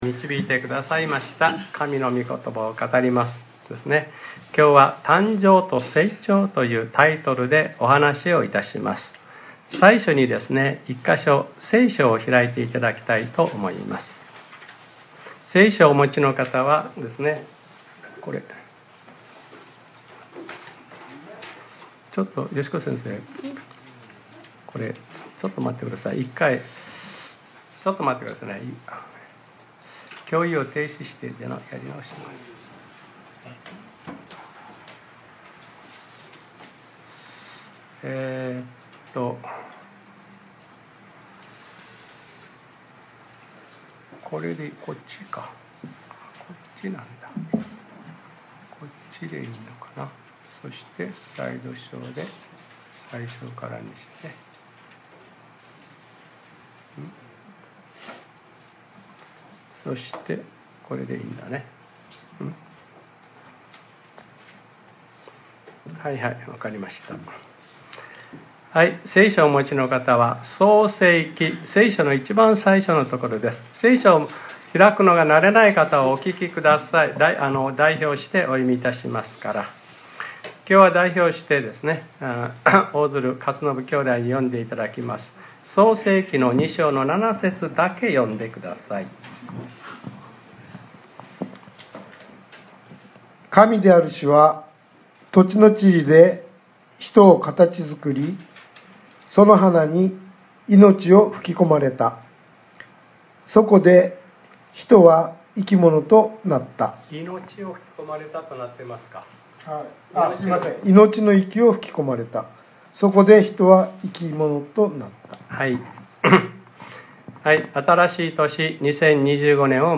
2025年01月05日（日）礼拝説教『 誕生と成長 』 | クライストチャーチ久留米教会